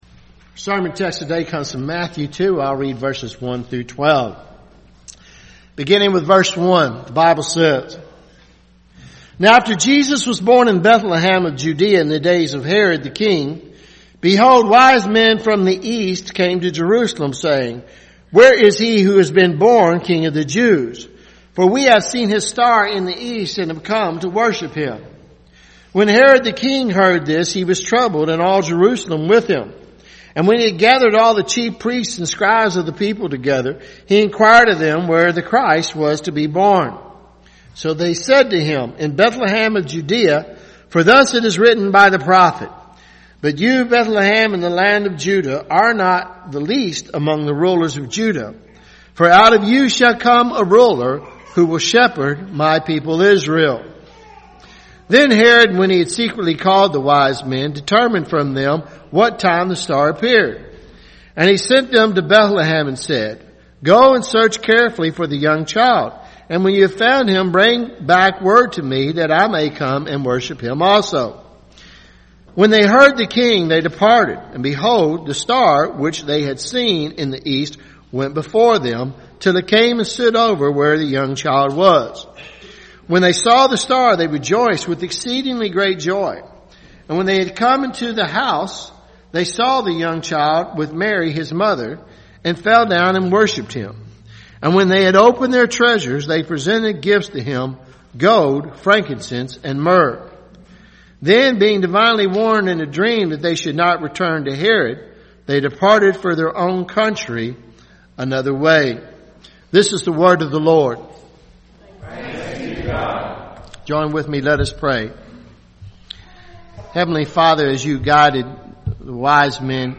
Sermons Jan 06 2019 “The Magi Visit Jesus
6-January-2019-sermon.mp3